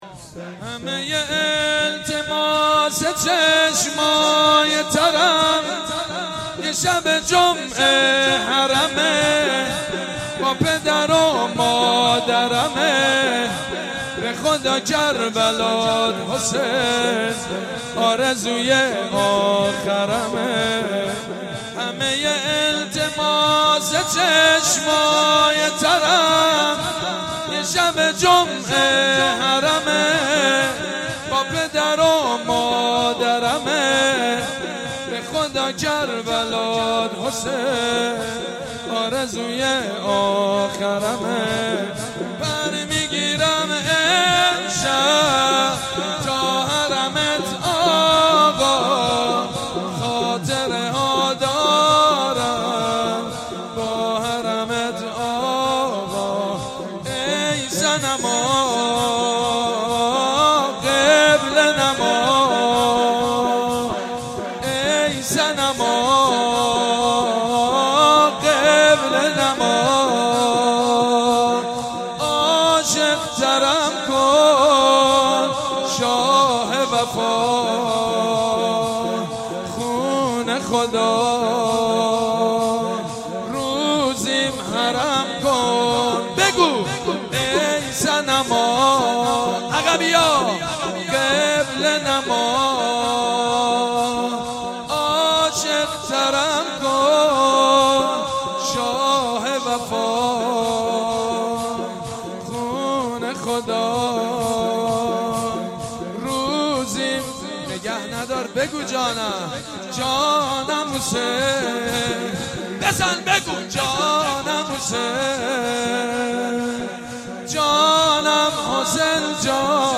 مداح
قالب : شور